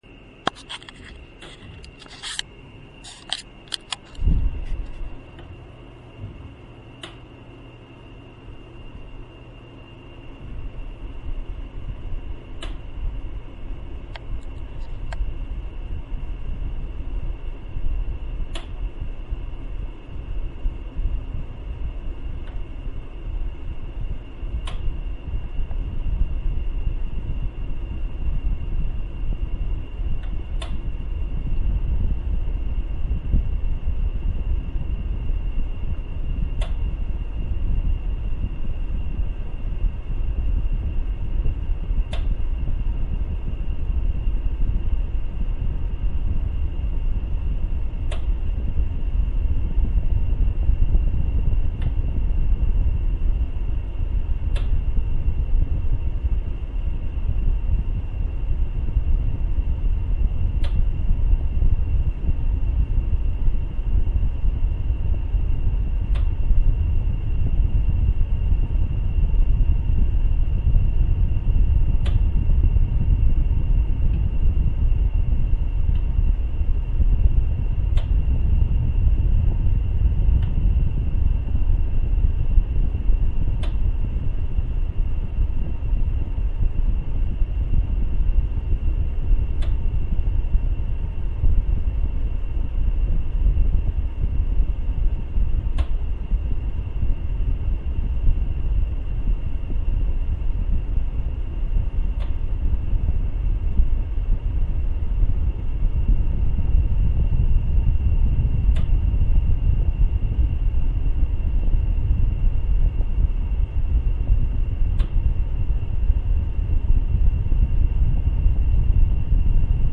Bruit climatiseur réversible Mitsubishi
J'ai un bloc climatiseur réversible posé en haut du mur dans le couloir qui mène au chambre.
Depuis l'été dernier, nous constatons un bruit "clac clac" qui est très gênant la nuit.
On dirait un bruit de relais ou contacteur qui s'enclenche et se déclenche, pour la source exacte il faudrait que le technicien ait une approche plus saine, ouvrir l'UE et essayer de définir le problème.
Le bruit arrive toutes les 6/7 secondes...
Pour le bruit en fait, parfois c'est rapproché parfois moins rapproché.
bruit-climatiseur-reversible-mitsubishi.mp3